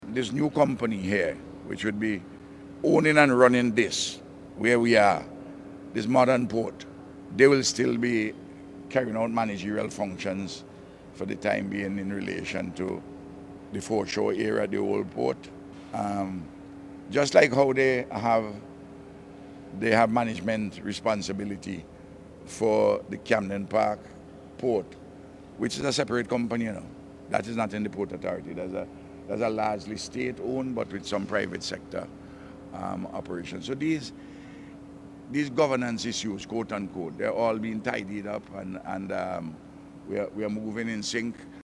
This is according to Prime Minister Dr. Ralph Gonsalves who spoke to the Agency for Public Information during a tour of the facility this morning.